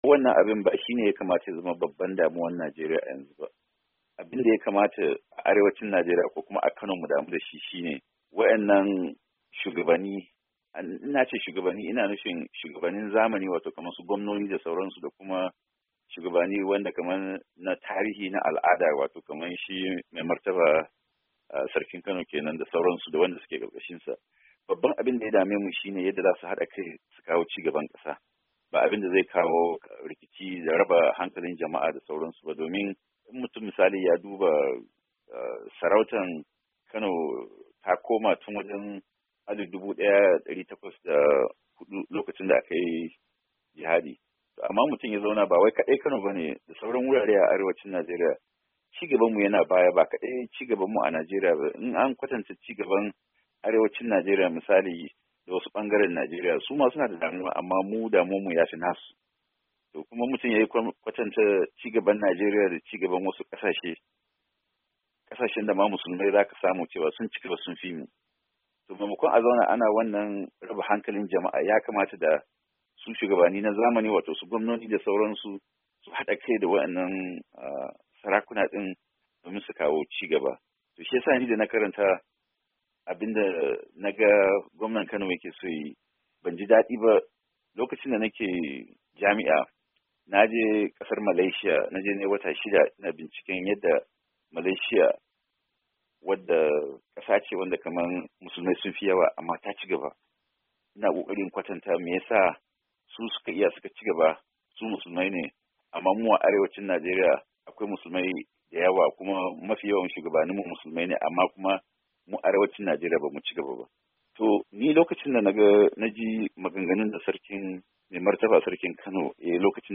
Masu Fashin Baki Na Ci Gaba Da Tsokaci Akan Dokar Kirkiro Da Sabbin Masarautu A Jihar Kano